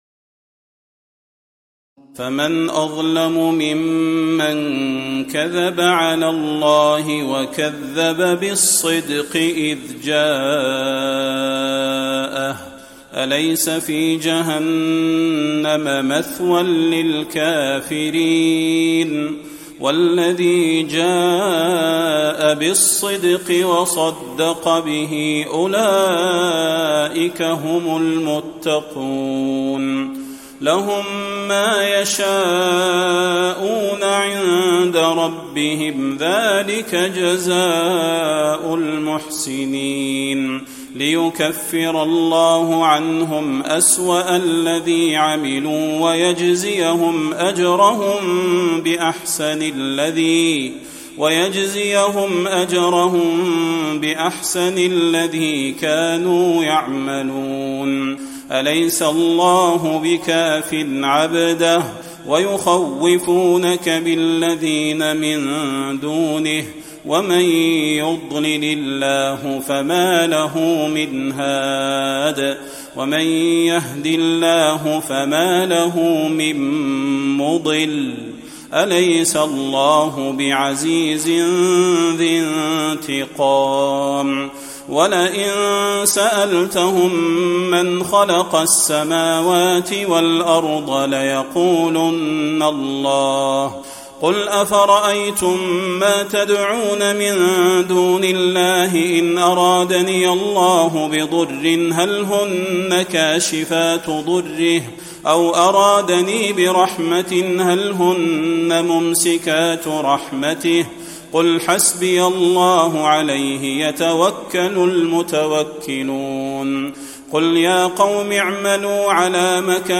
تراويح ليلة 23 رمضان 1435هـ من سور الزمر (32-75) و غافر (1-37) Taraweeh 23 st night Ramadan 1435H from Surah Az-Zumar and Ghaafir > تراويح الحرم النبوي عام 1435 🕌 > التراويح - تلاوات الحرمين